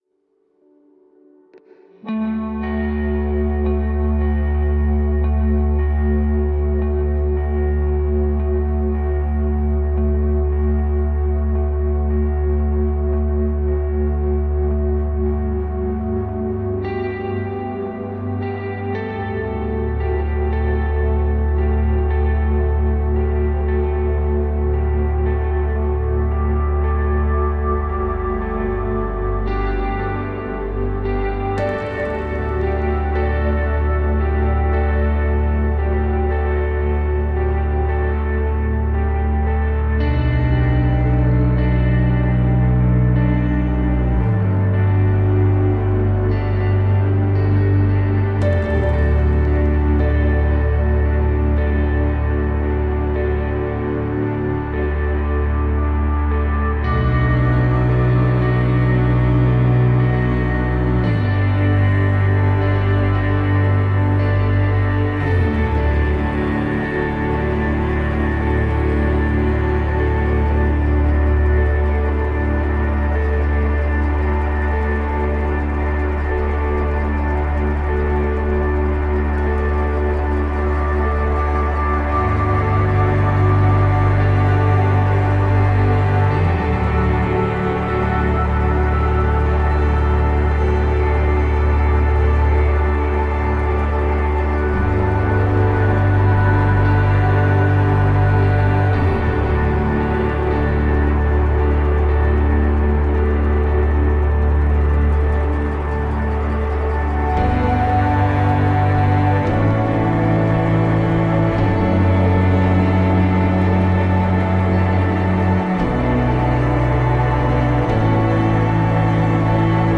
Ambient, melodic electronica meets neo-classical.